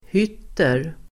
Uttal: [h'yt:er el. höt:er]